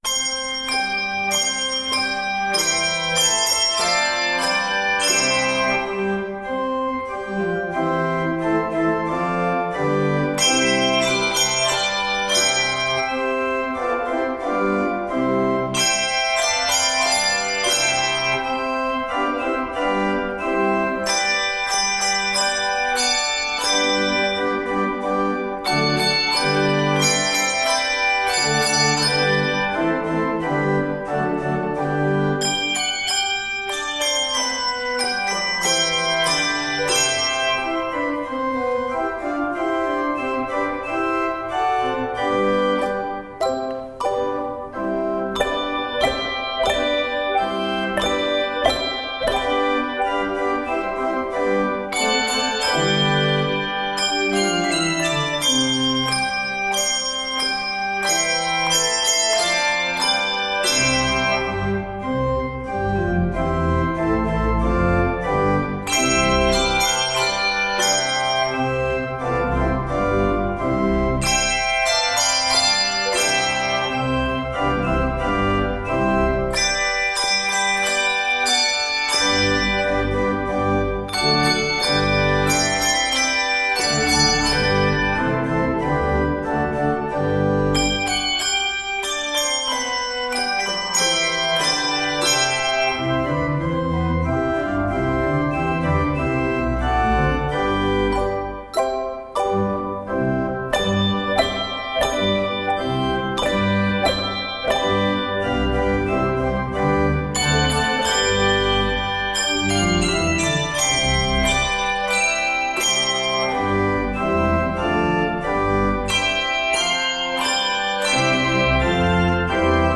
antiphonal hymn tune
arranged for handbells and organ
Key of C Major.